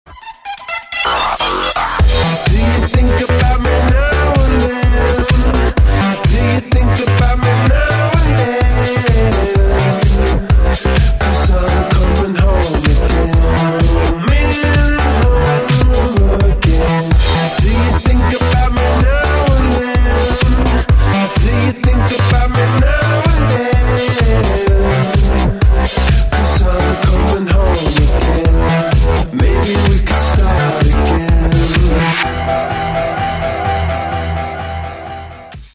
• Качество: 128, Stereo
ритмичные
мужской вокал
громкие
dance
Electronic
EDM
электронная музыка
клавишные
house